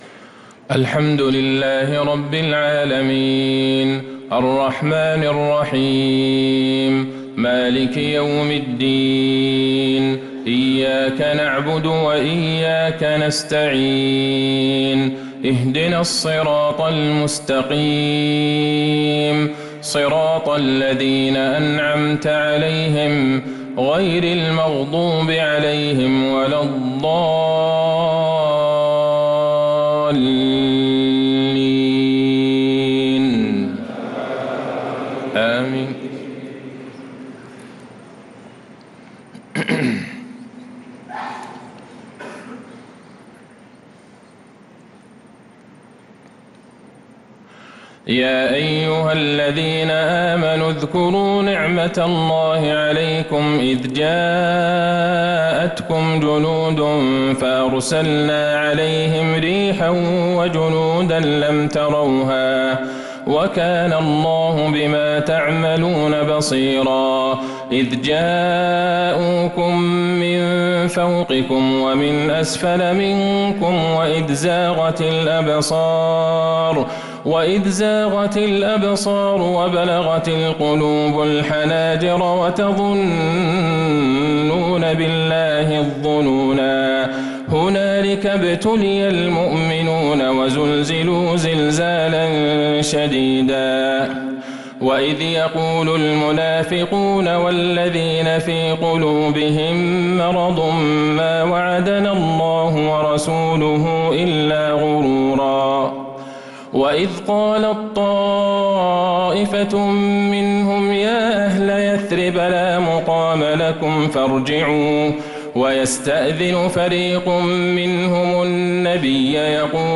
عشاء الثلاثاء 6 محرم 1447هـ من سورة الأحزاب 9-22 | Isha prayer from surah Al-ahzab 1-7-2025 > 1447 🕌 > الفروض - تلاوات الحرمين